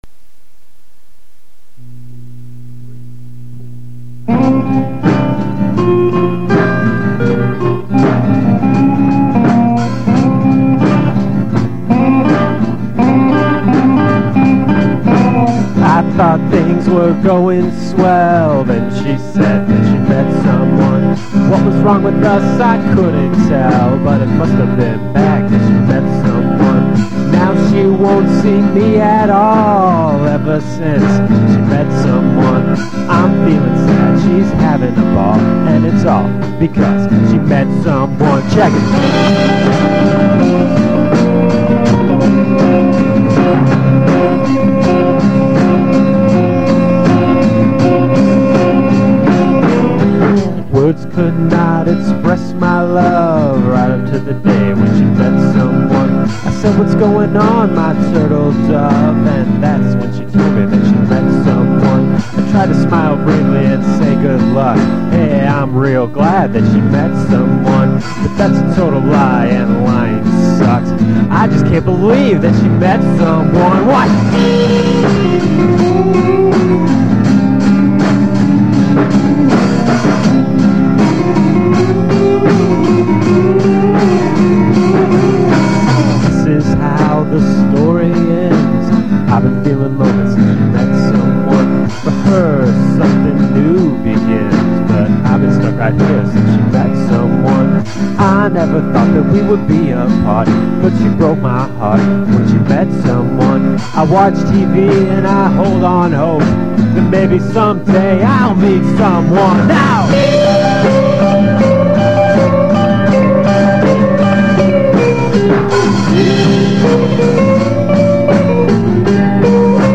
From a St. Louis basement comes a lo-fi gem.